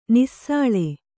♪ nissāḷe